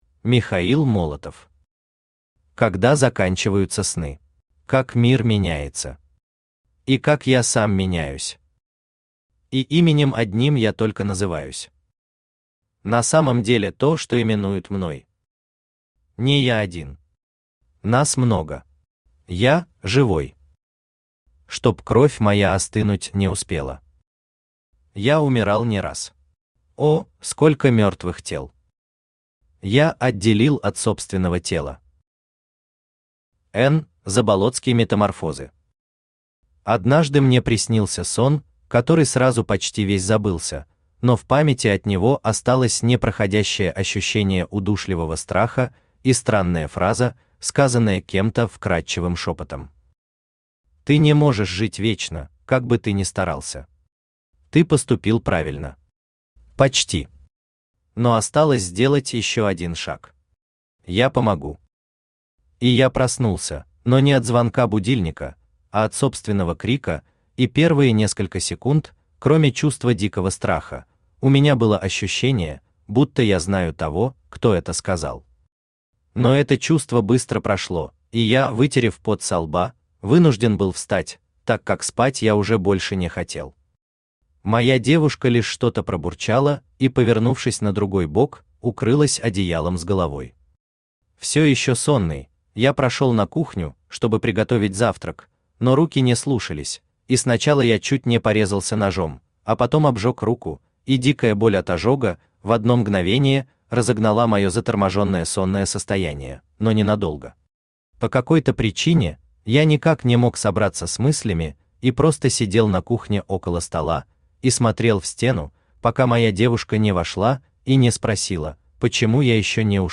Аудиокнига Когда заканчиваются сны | Библиотека аудиокниг
Aудиокнига Когда заканчиваются сны Автор Михаил Степанович Молотов Читает аудиокнигу Авточтец ЛитРес.